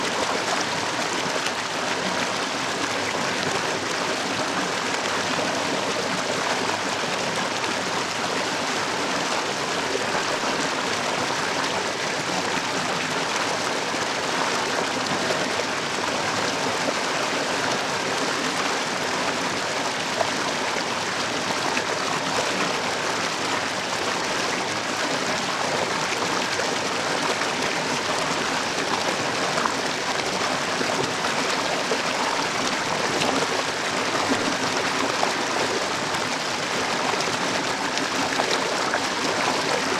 Sounds / Water / FlowSmall.ogg